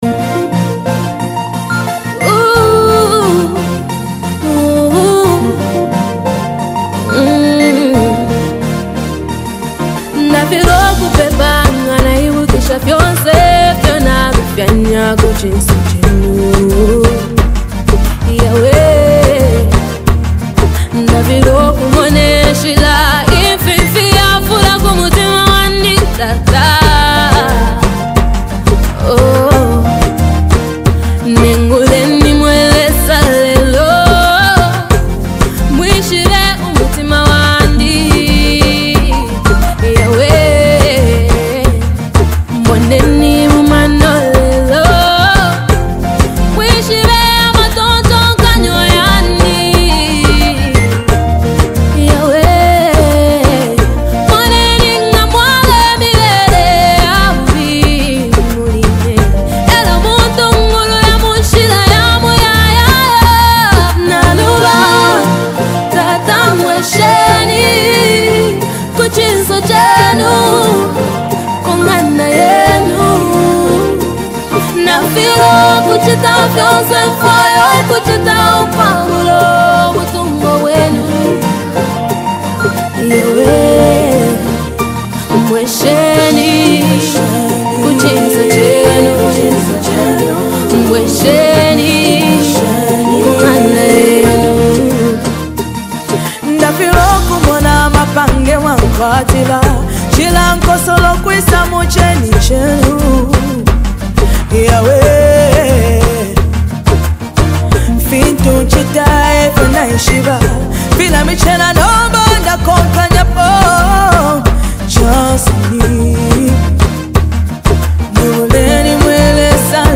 the talented Zambian gospel artist